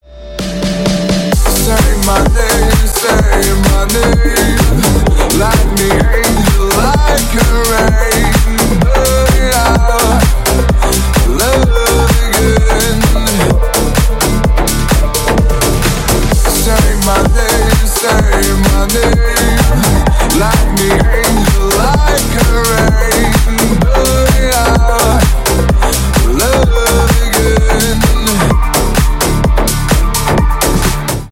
клубные , club house
edm